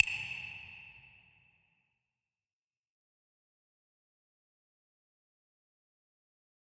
menuclick.ogg